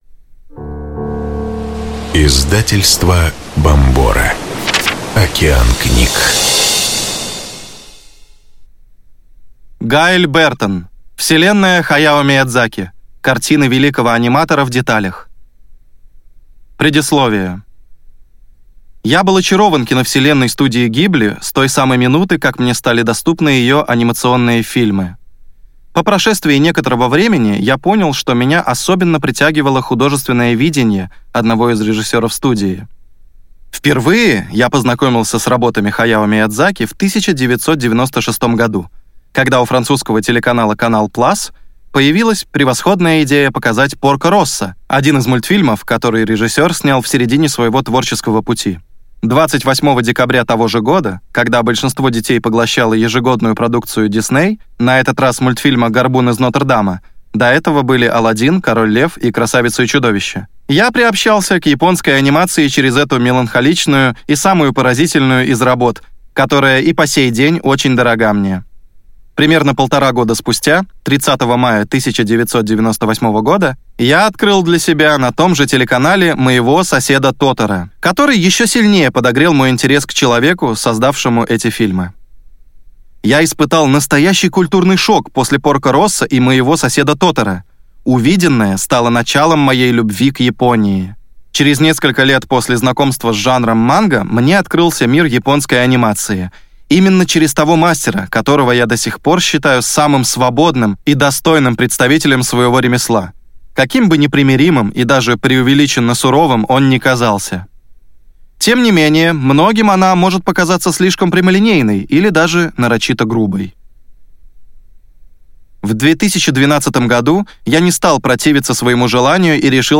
Аудиокнига Вселенная Хаяо Миядзаки. Картины великого аниматора в деталях | Библиотека аудиокниг